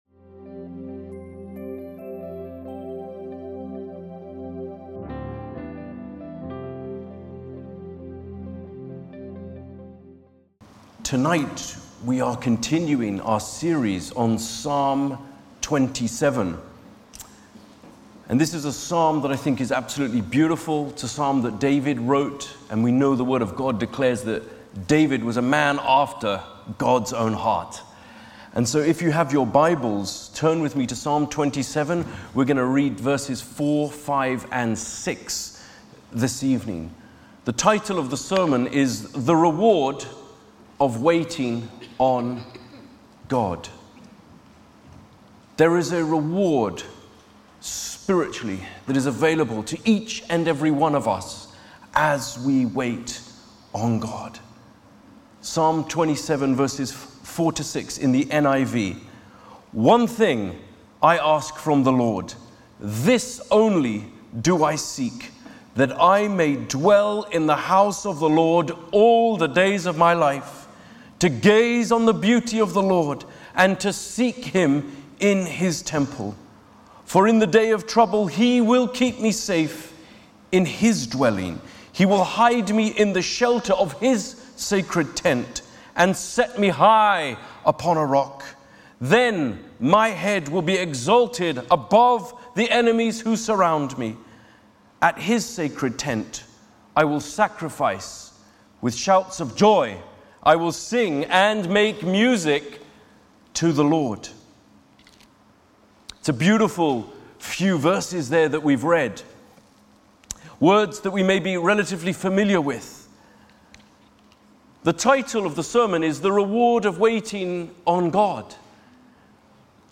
_Sermon Series